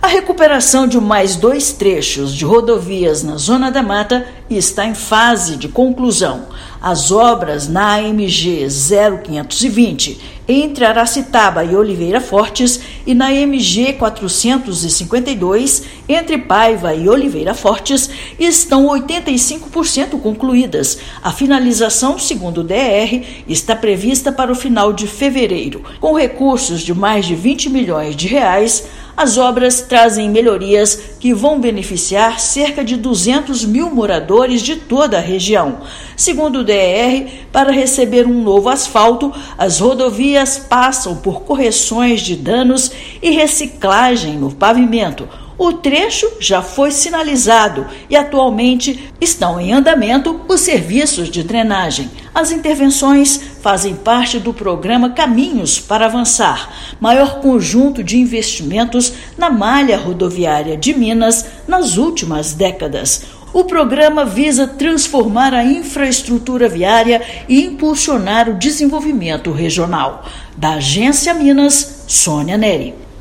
Melhorias na AMG-0520 e na MG-452 beneficiam cerca de 200 mil pessoas da região. Ouça matéria de rádio.